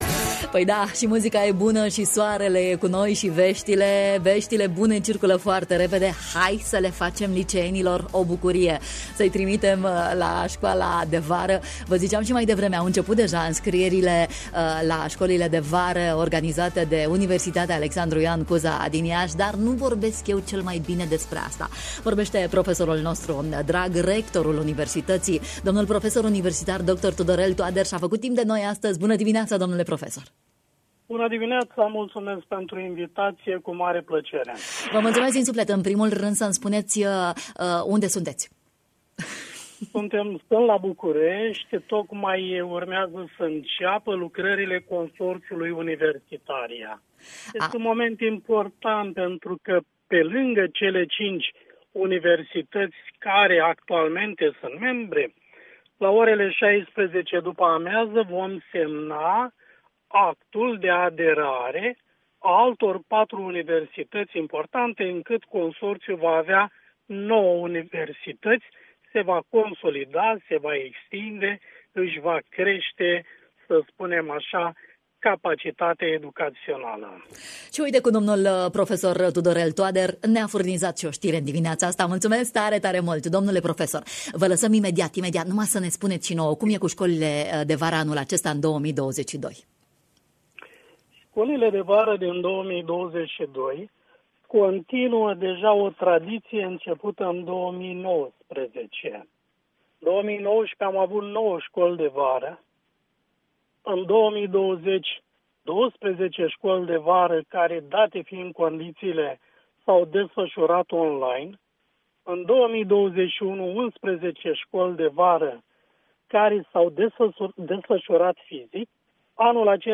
Elevii care studiază la unul dintre liceele eligibile în cadrul Proiectului ROSE se pot înscrie, până la data de 2 iunie 2022, la una dintre cele 11 școli de vară. Rectorul UAIC Iaşi, prof.dr. Tudorel Toader ne-a vorbit în matinalul de la Radio România Iaşi: Share pe Facebook Share pe Whatsapp Share pe X Etichete: